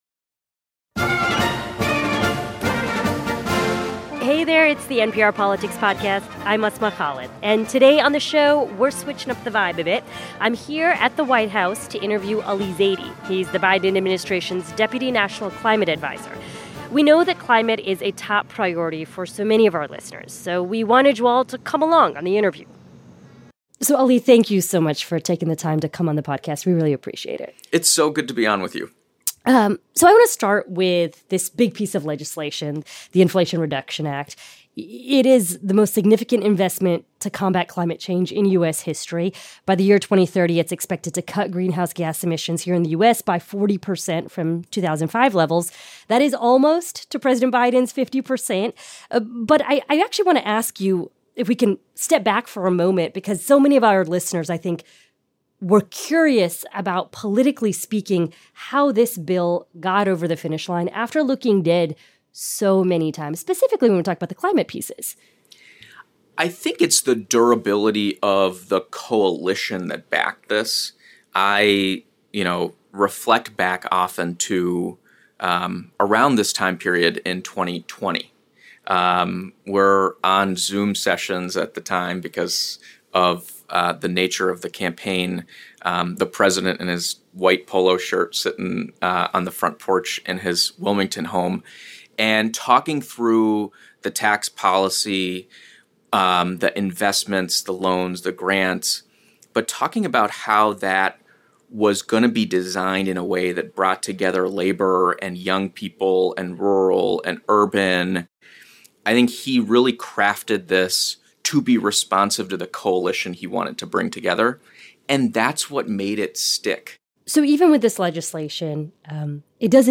The U.S. just passed its first major climate legislation. NPR's Asma Khalid sat down at the White House with Deputy National Climate Adviser Ali Zaidi to talk about what is next for the Biden administration's climate agenda and whether its policy legacy could withstand a future Republican administration.